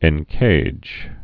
(ĕn-kāj)